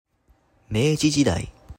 The Meiji era (明治時代, Meiji jidai, [meꜜː(d)ʑi]